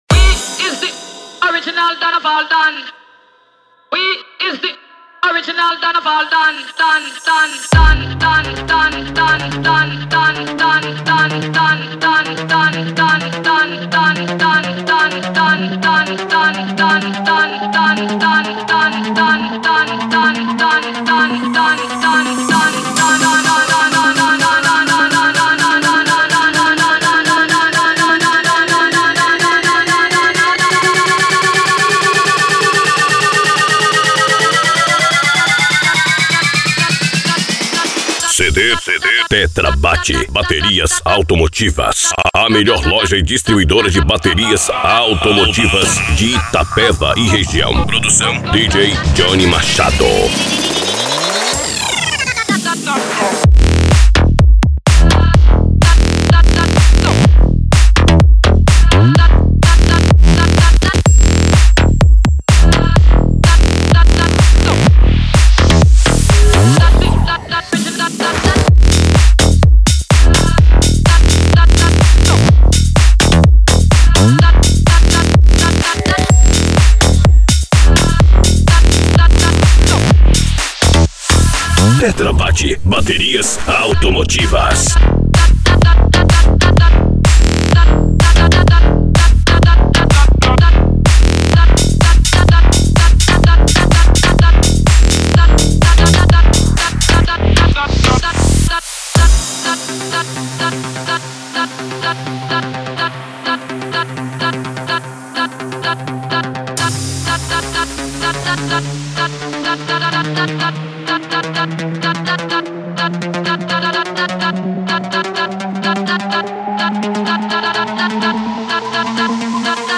Eletronica